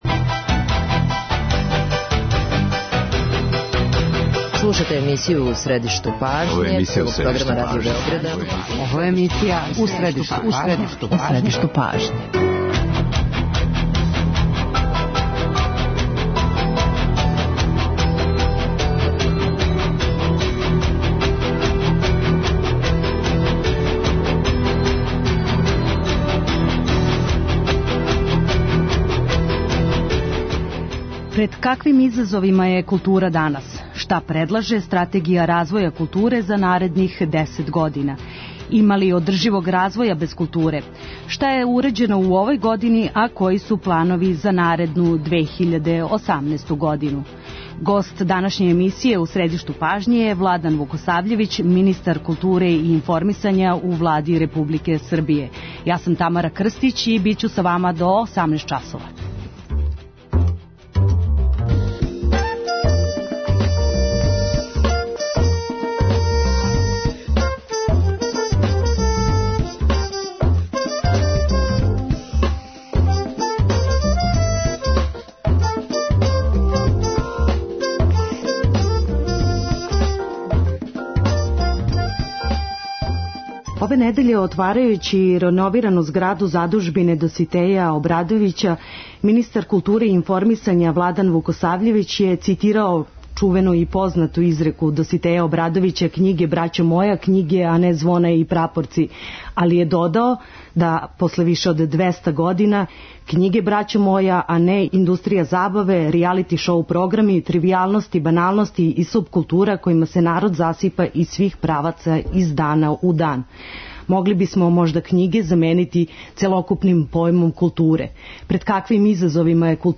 Гост емисије је Владан Вукосављевић, министар културе и информисања у Влади Републике Србије.